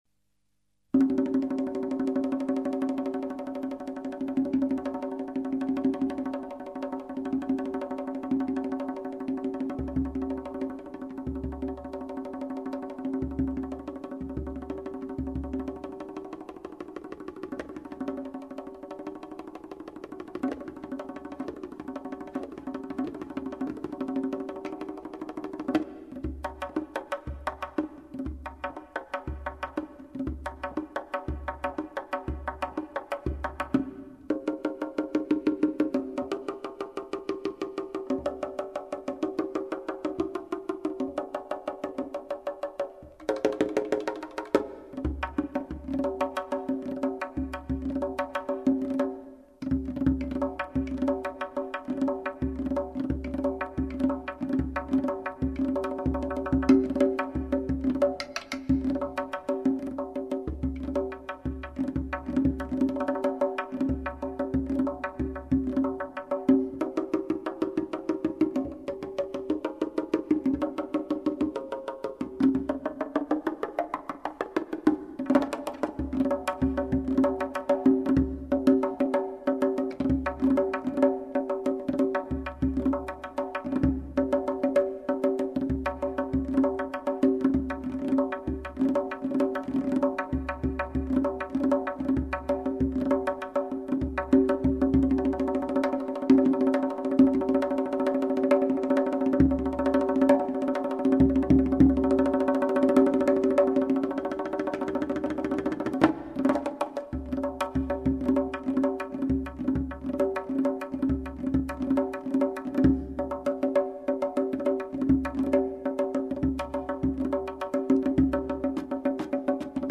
صدای ساز تنبک
تنبک یک ساز کوبه ای ایرانی با کوک نامعین است.
صدای-ساز-تنبک.mp3